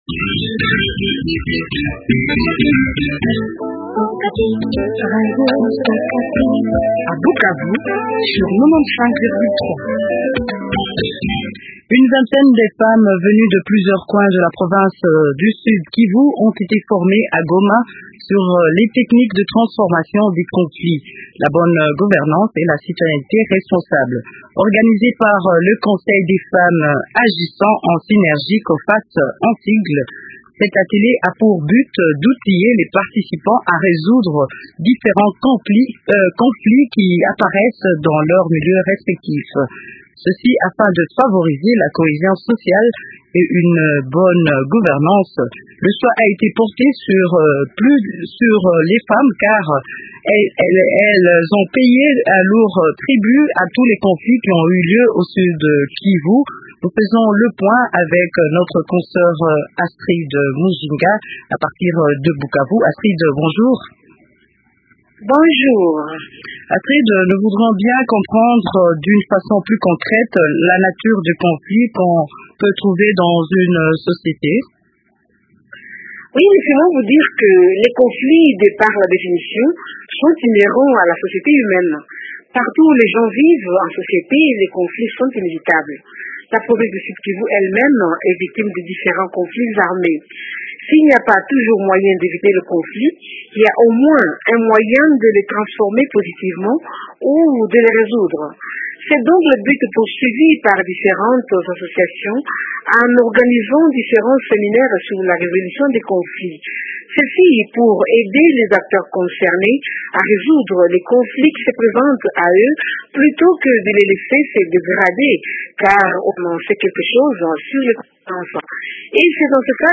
au téléphone